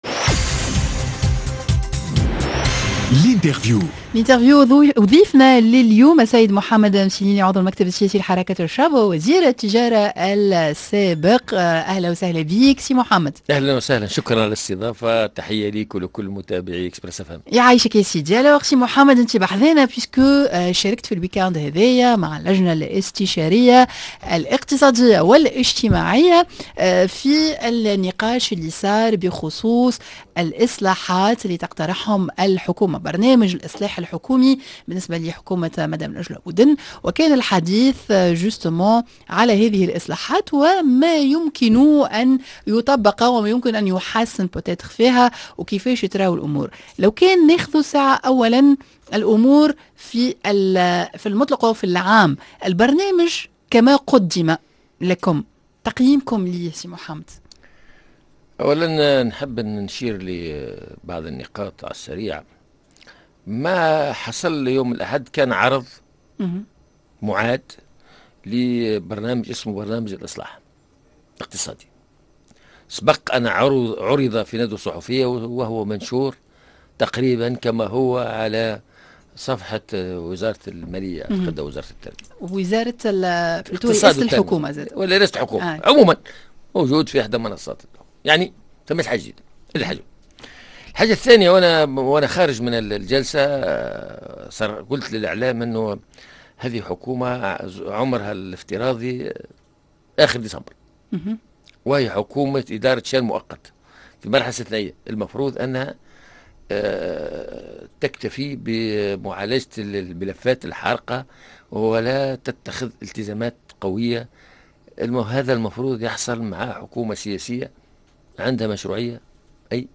L'interview: اللجنة الاستشارية تناقش برنامج اصلاحات الحكومة / محمد مسيليني عضو المكتب السياسي لحركة الشعب ووزير التجارة السّابق